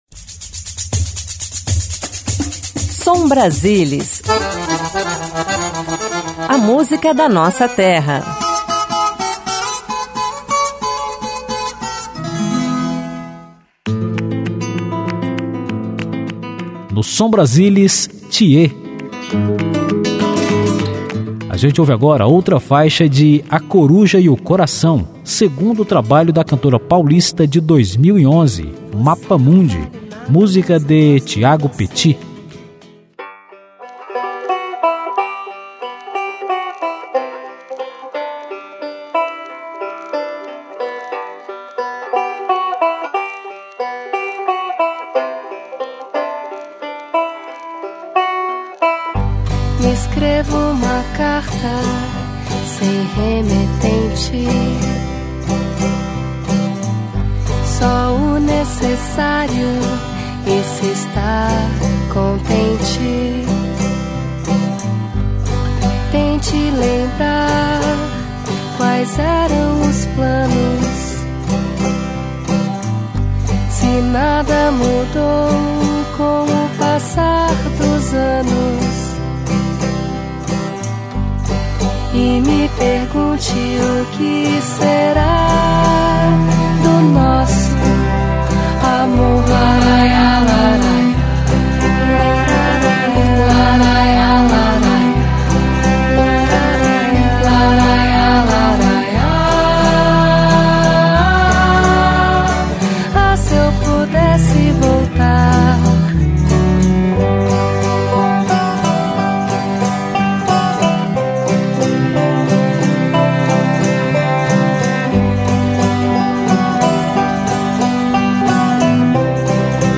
MPB Pop Rock Folk Indie pop